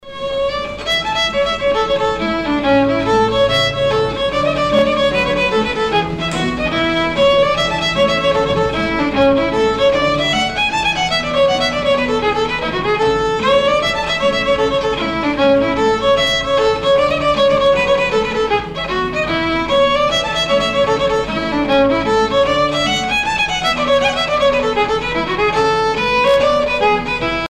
danse : hornpipe
Pièce musicale éditée